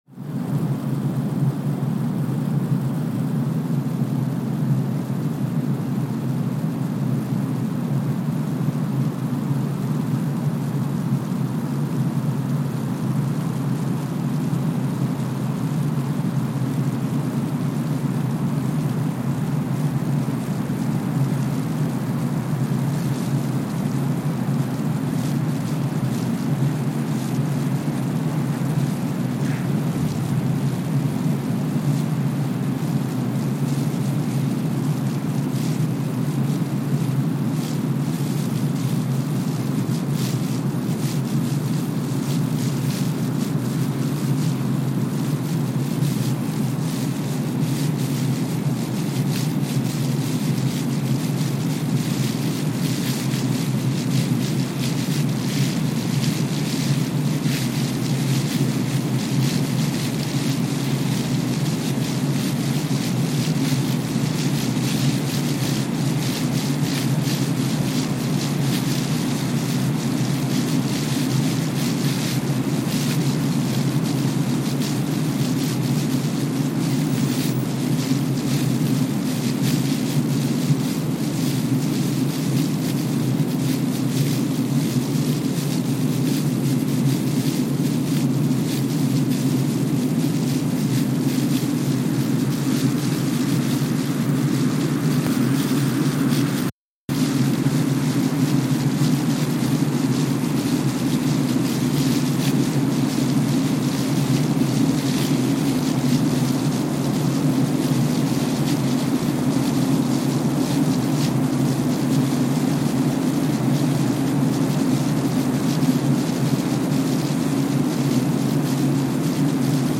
Kwajalein Atoll, Marshall Islands (seismic) archived on October 21, 2023
Sensor : Streckeisen STS-5A Seismometer
Speedup : ×1,000 (transposed up about 10 octaves)
Loop duration (audio) : 05:45 (stereo)